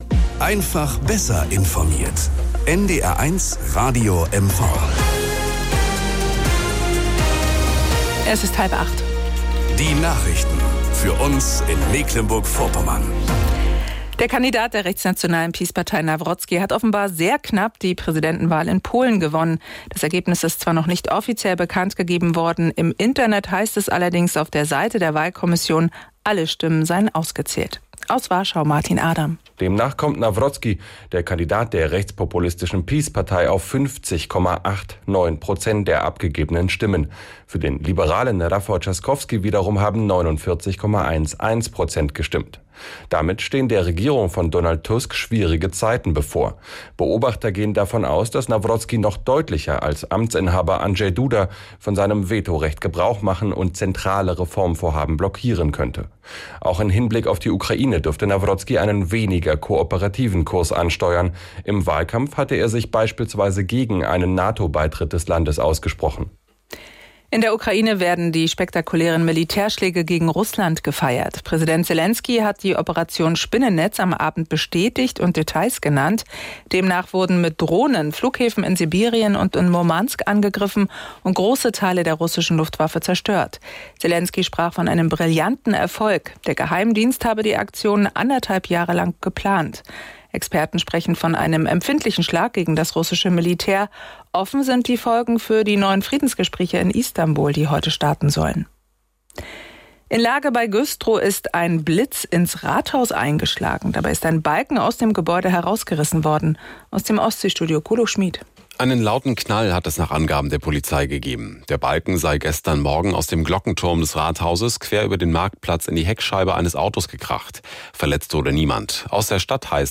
Nachrichten aus Mecklenburg-Vorpommern - 01.07.2025